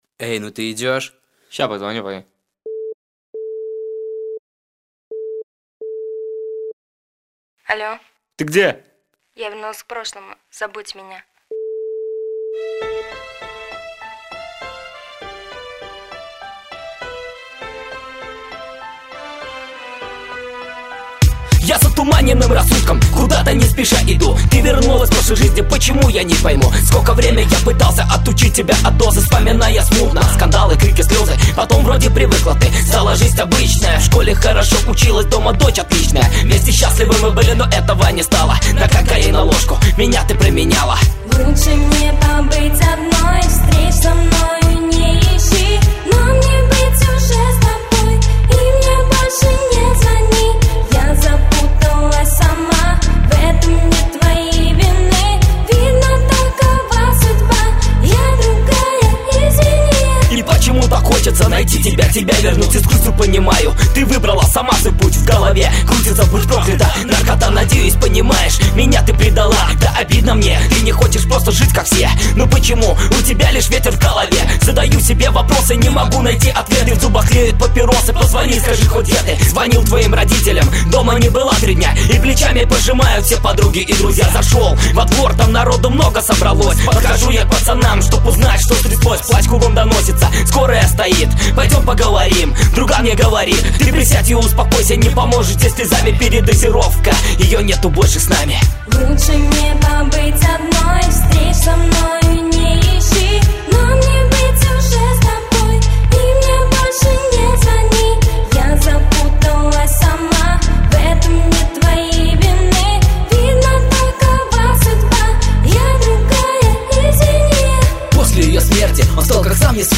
Категория: Пацанский рэп